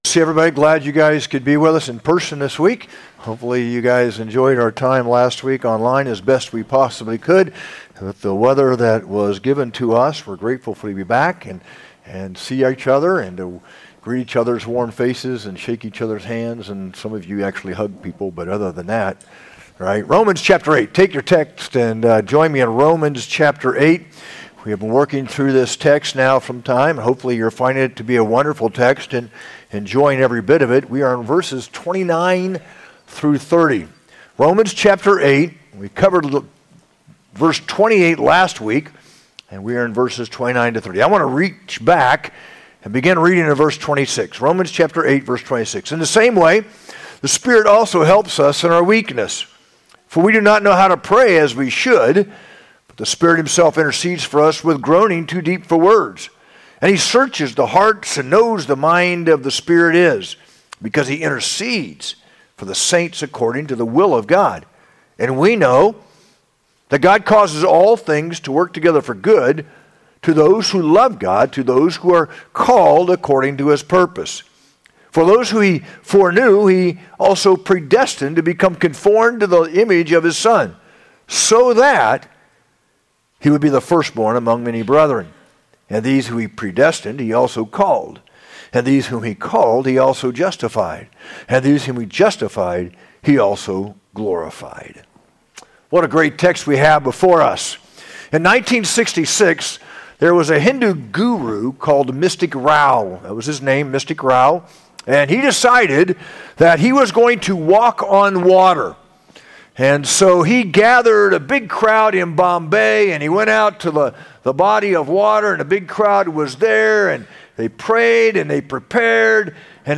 sermon-1-12-25.mp3